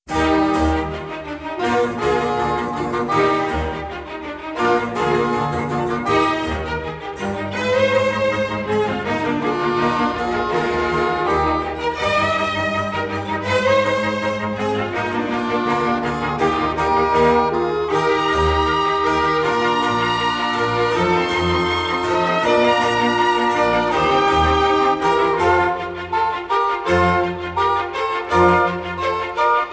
in E flat major
Presto